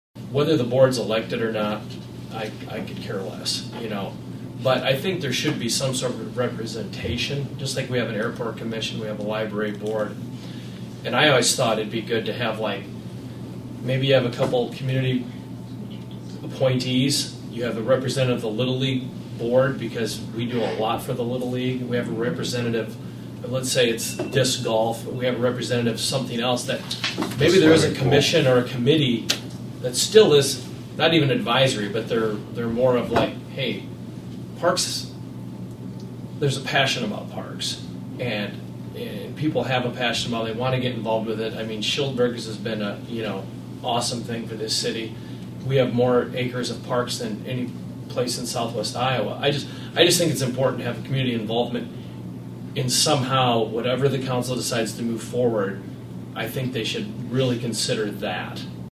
(Atlantic) Future plans for the Parks and Recreation Department were discussed at the Parks and Recreation Board Meeting Monday evening.